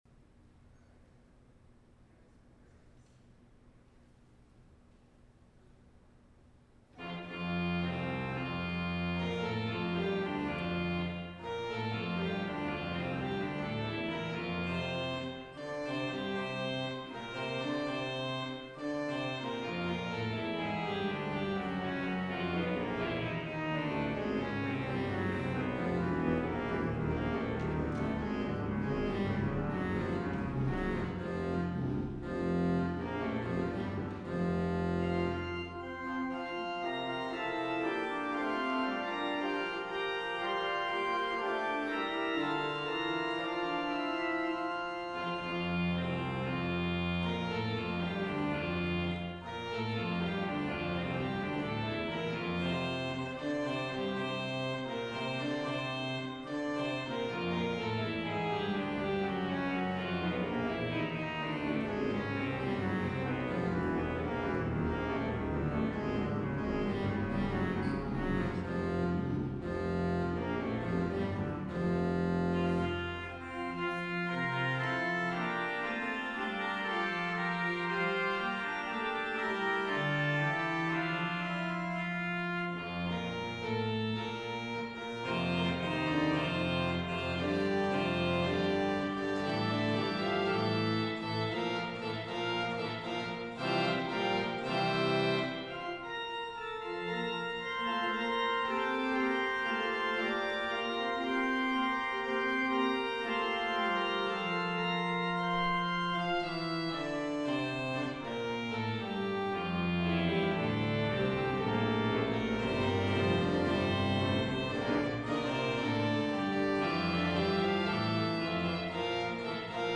Sunday Worship 9-13-20 (Fifteenth Sunday After Pentecost)